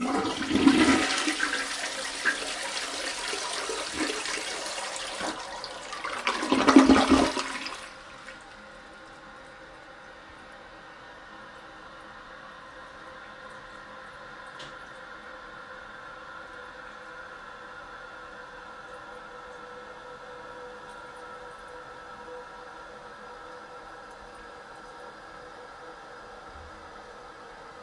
bathroom » Toilet Flush 02
描述：to flush the loo
标签： flush plumbing bathroom toilet water
声道立体声